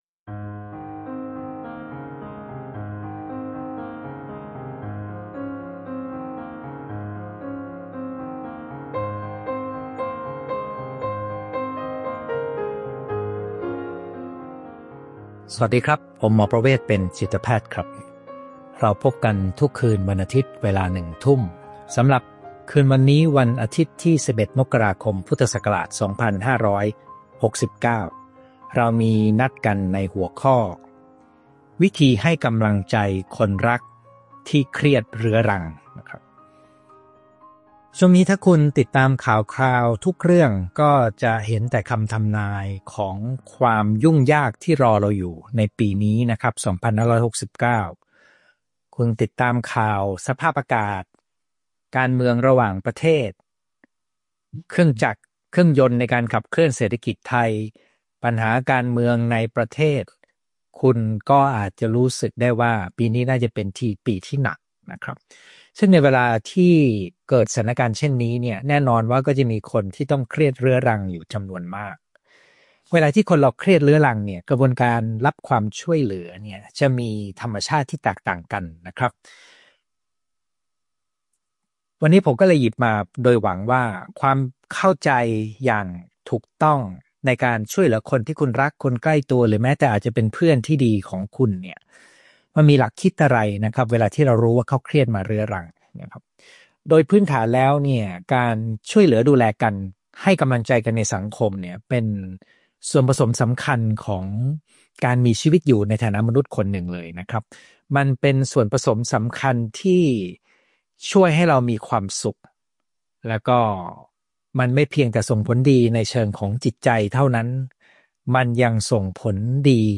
ไลฟ์ประจำวันอาทิตย์ที่ 11 มกราคม 2569 เวลาหนึ่งทุ่ม